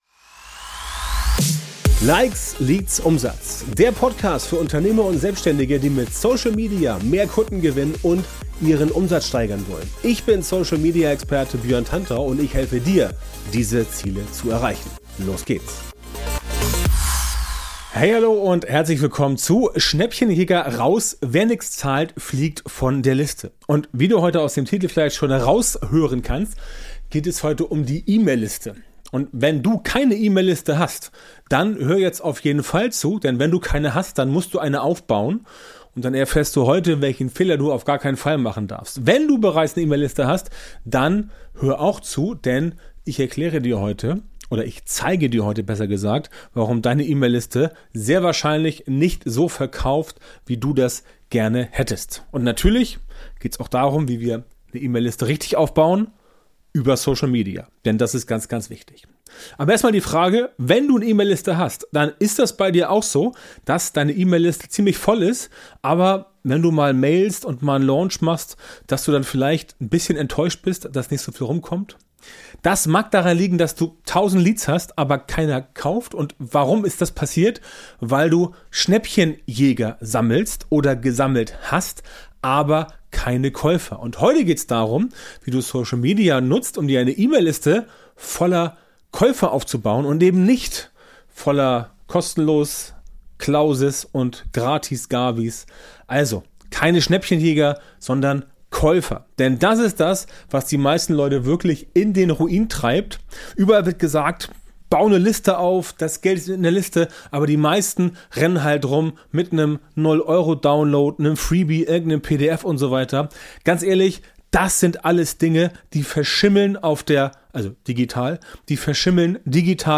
Wie die "TikTokisierung" soziale Netzwerke verändert | Interview